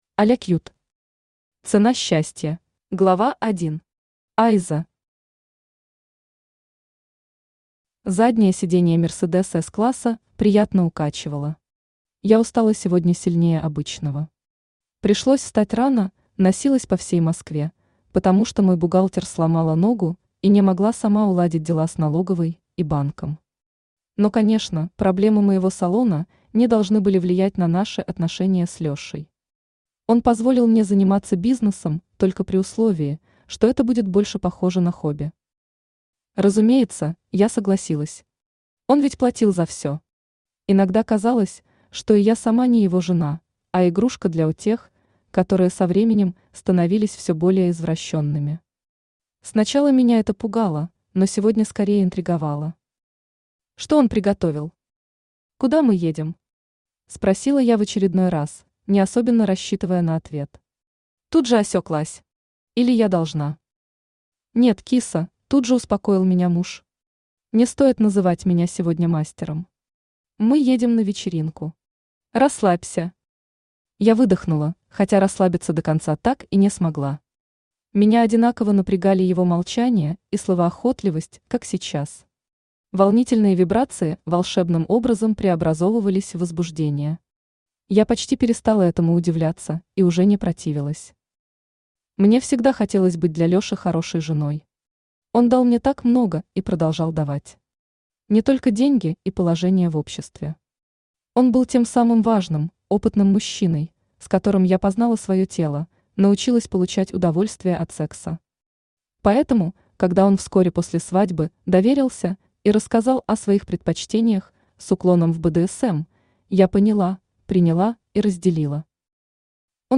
Аудиокнига Цена счастья | Библиотека аудиокниг
Aудиокнига Цена счастья Автор Аля Кьют Читает аудиокнигу Авточтец ЛитРес.